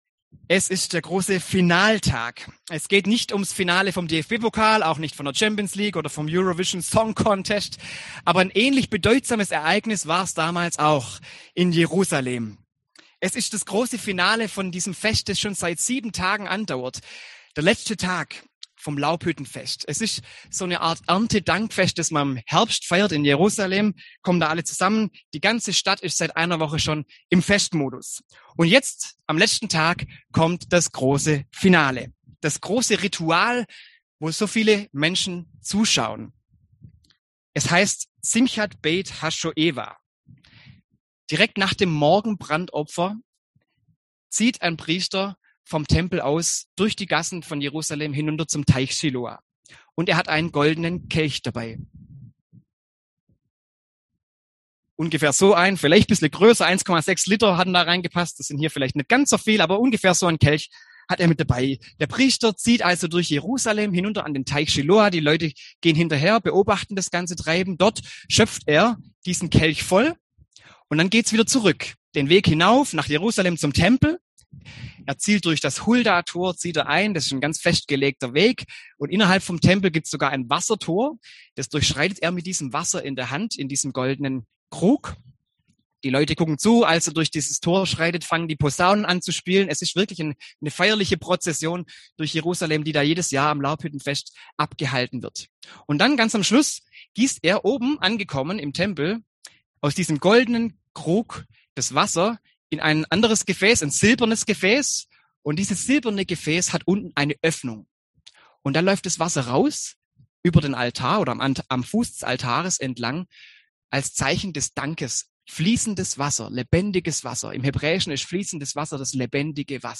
Predigt zu Johannes 7,37-39: Jesu Ruf auf dem Laubhüttenfest Thema: Lebendiges Wasser - Glauben wie die Gießkanne Dazu: Jesus und die Frau am Brunnen (Johannes 4) Das Skript zum Nachlesen hier als PDF zum Download: Wie die Gießkanne